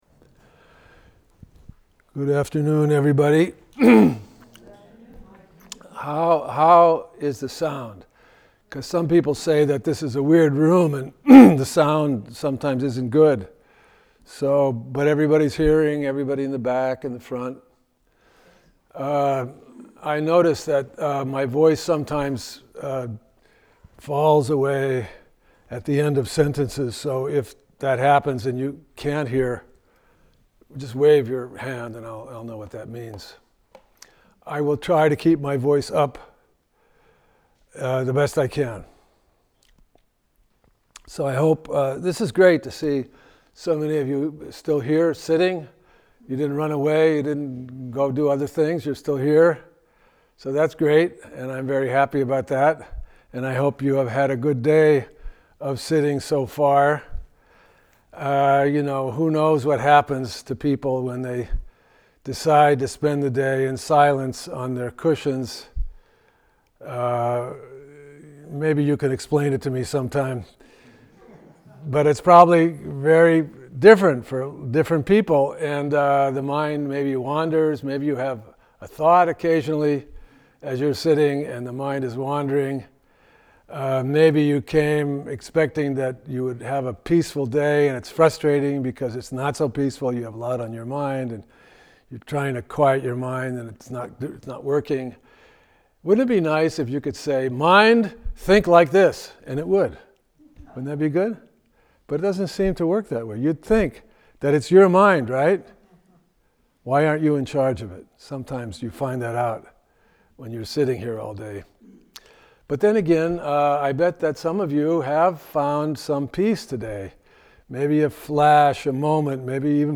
Gandavyuha Sutra (Avatamsaka Sutra Chapter 39) – Talk 8 – All Day Sitting September 2025